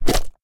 minecraft / sounds / mob / slime / attack2.ogg
attack2.ogg